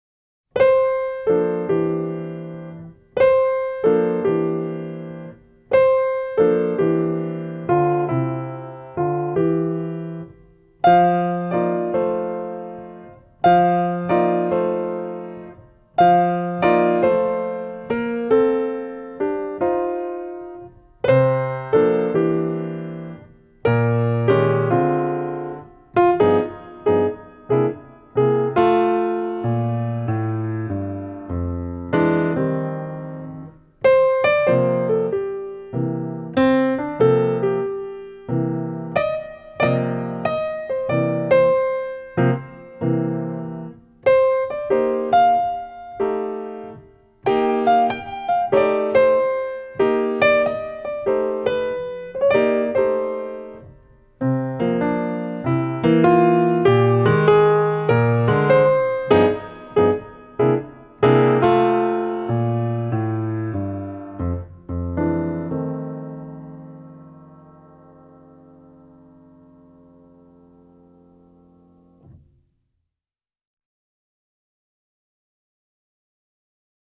Methods - Piano
2 mains